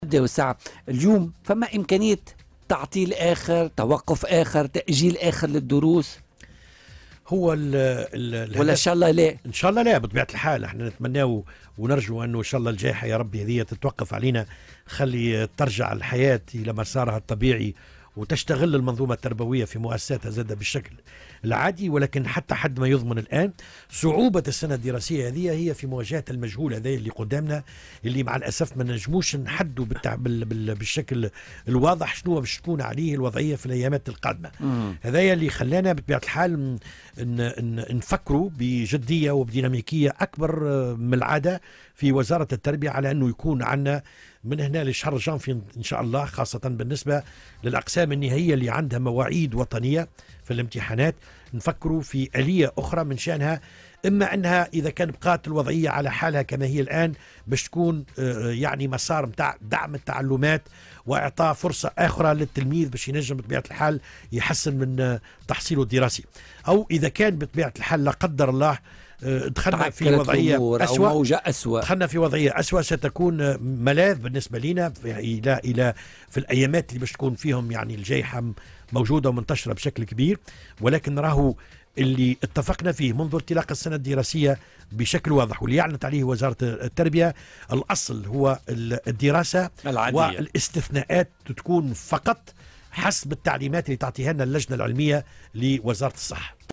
وقال في مداخلة له اليوم على "الجوهرة أف أم" أن الأصل هو مواصلة الدراسة والاستثناءات تكون فقط حسب تعليمات اللجنة العلمية التابعة لوزارة الصحة".